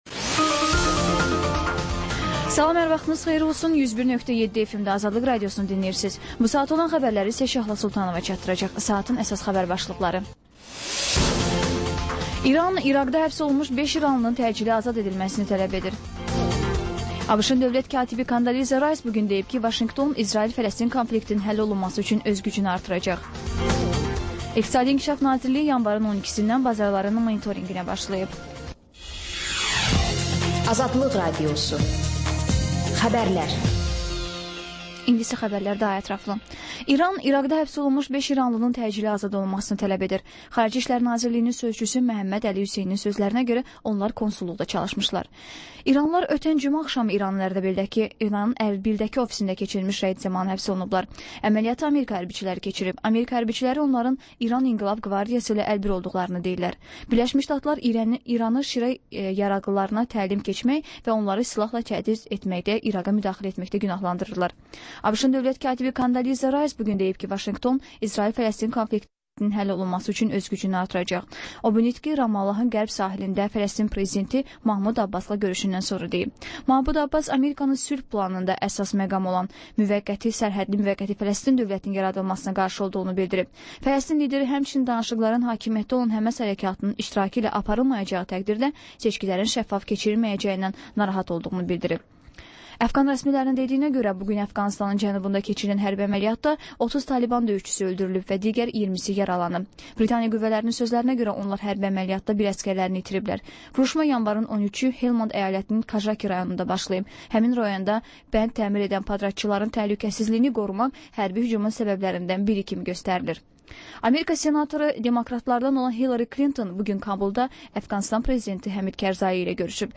Xəbərlər, reportajlar, müsahibələr. Və: İZ: Mədəniyyət proqramı.